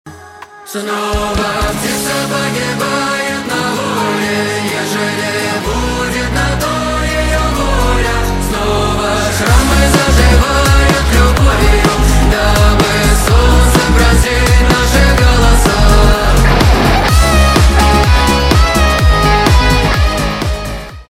2024 » Новинки » Русские » Поп Скачать припев